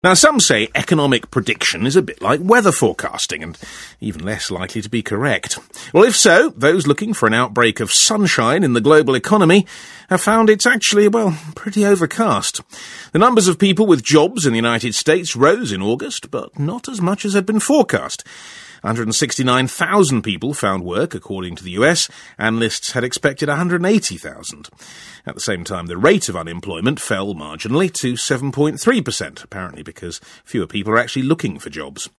【英音模仿秀】冻云黯淡 晚来是秋 听力文件下载—在线英语听力室